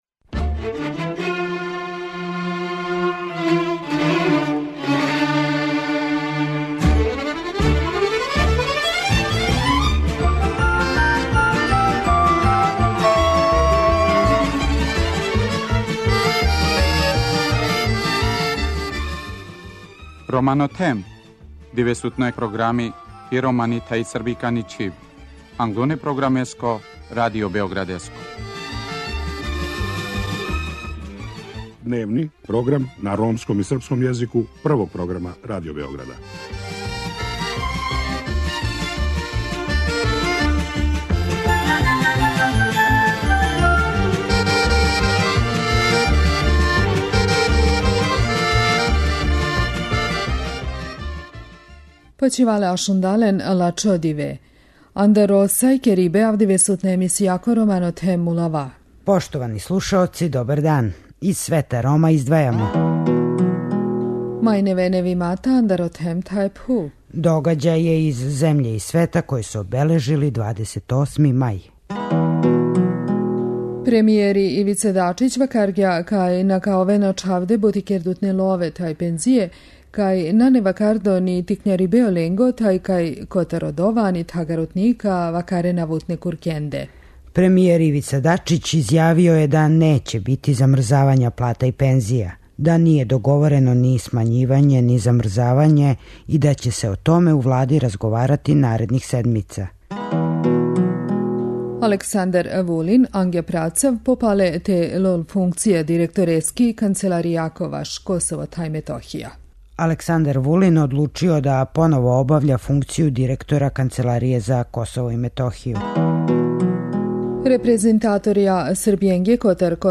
Агенцијске вести на ромском језику.